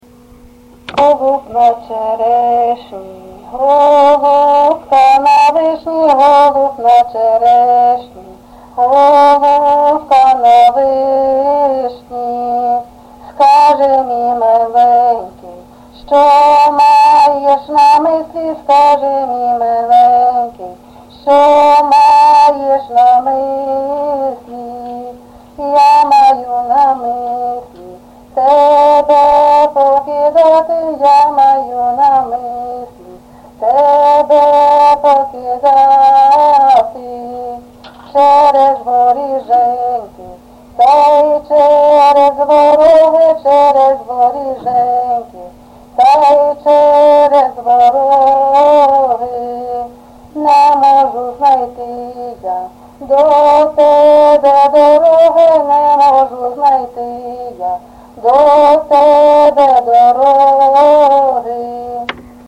ЖанрПісні з особистого та родинного життя
Місце записус. Серебрянка, Артемівський (Бахмутський) район, Донецька обл., Україна, Слобожанщина